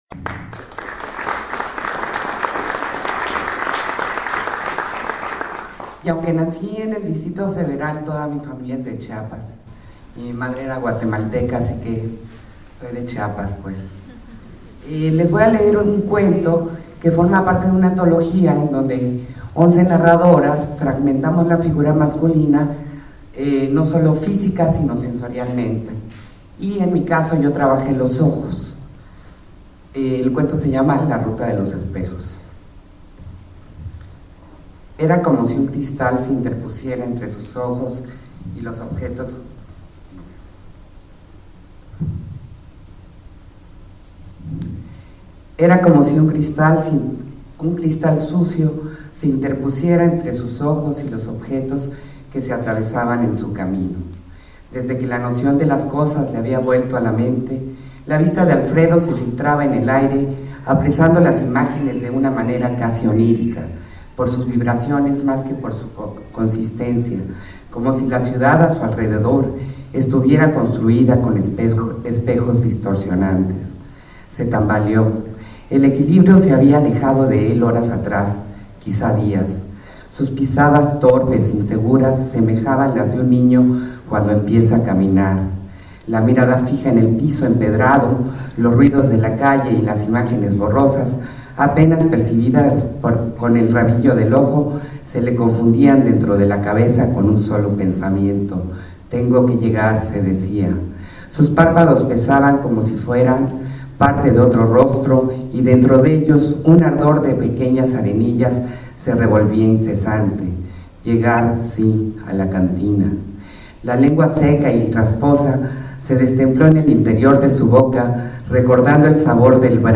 Lugar: Teatro Daniel Zebadúa de San Cristóbal de Las Casas, Chiapas.
Equipo: iPod 2Gb con iTalk Fecha: 2008-11-06 12:52:00 Regresar al índice principal | Acerca de Archivosonoro